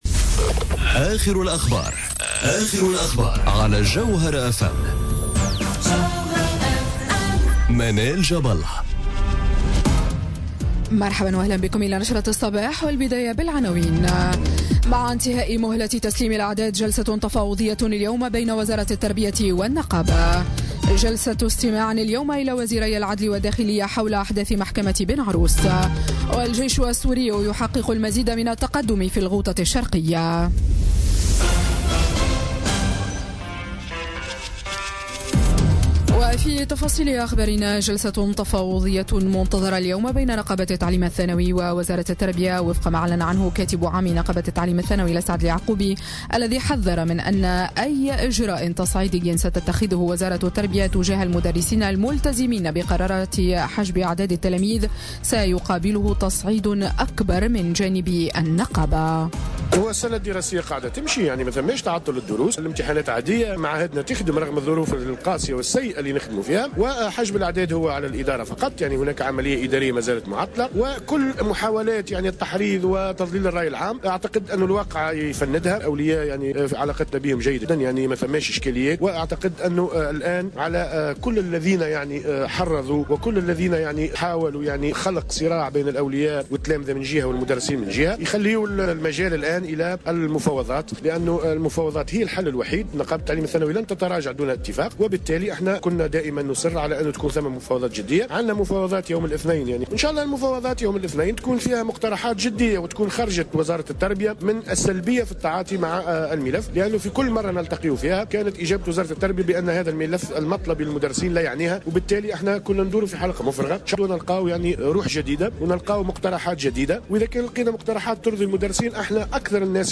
نشرة أخبار السابعة صباحا ليوم الإثنين 12 مارس 2018